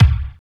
31.09 KICK.wav